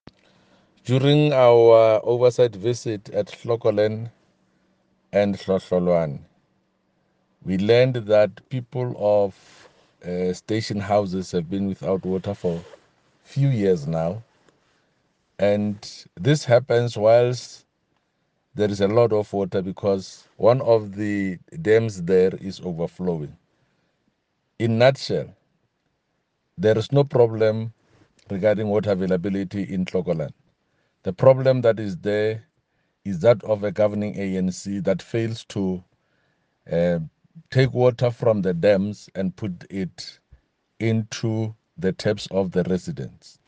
Sesotho soundbites by Jafta Mokoena MPL.